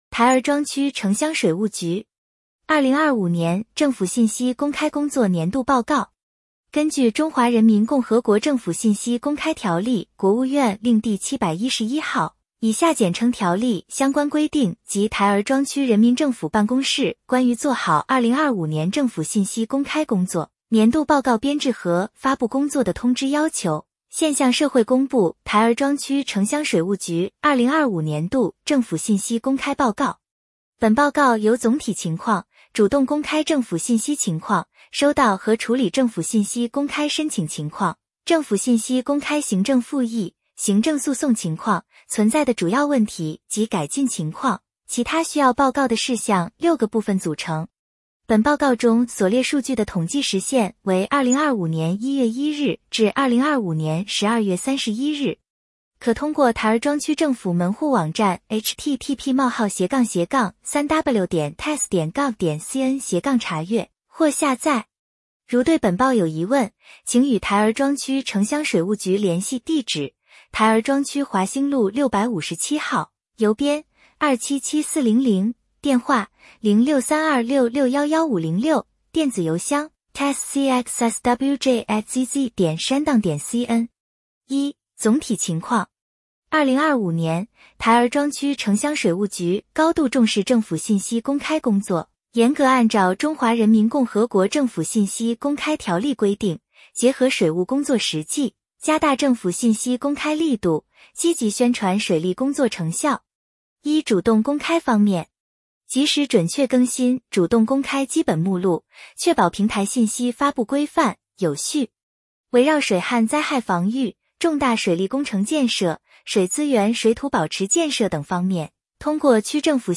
点击接收年报语音朗读 台儿庄区城乡水务局2025年政府信息公开工作年度报告 作者： 来自： 时间：2026-01-16 根据《中华人民共和国政府信息公开条例》（国务院令第711号，以下简称《条例》）相关规定及《台儿庄区人民政府办公室关于做好2025年政府信息公开工作年度报告编制和发布工作的通知》要求，现向社会公布台儿庄区城乡水务局2025年度政府信息公开报告。